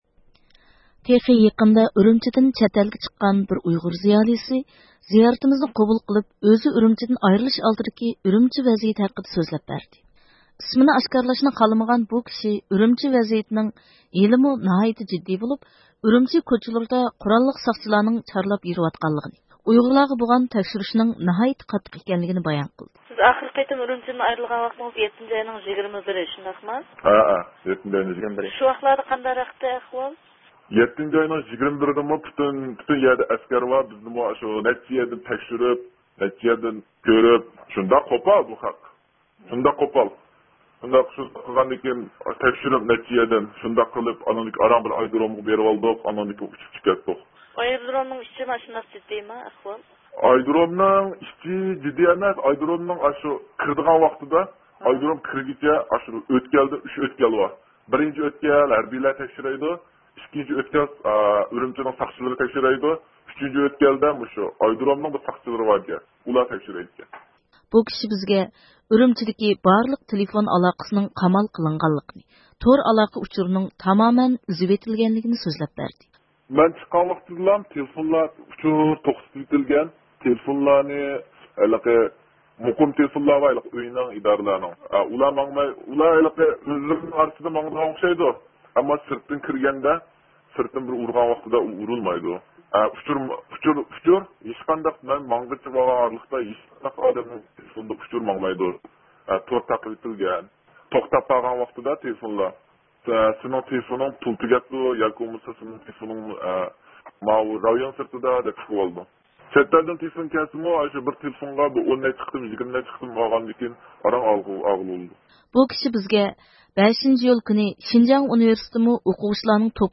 تېخى يېقىندا ئۈرۈمچىدىن چەتئەلگە چىققان بىر ئۇيغۇر زىيالىيسى، زىيارىتىمىزنى قوبۇل قىلىپ ئۆزى ئۈرۈمچىدىن ئايرىلىش ئالدىدىكى ئۈرۈمچى ۋەزىيىتى ھەققىدە سۆزلەپ بەردى.